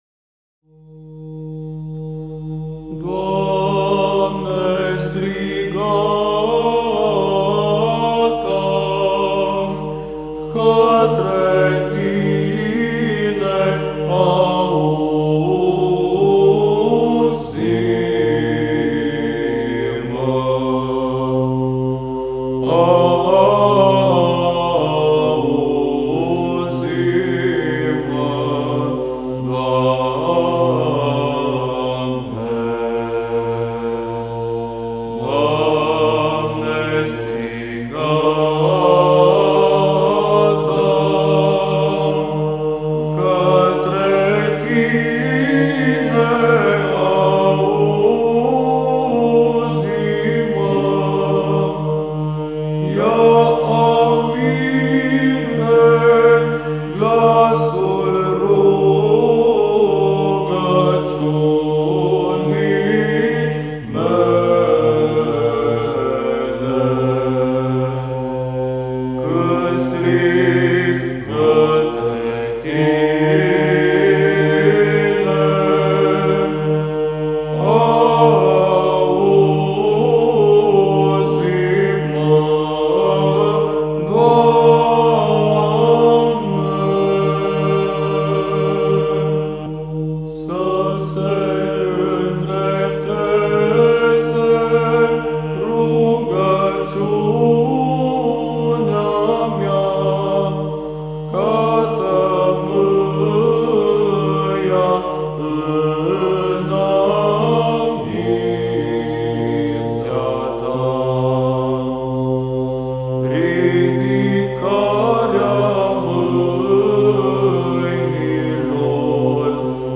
Glasurile bisericești – varianta de tradiție bizantină TRIFON LUGOJAN
GLAS III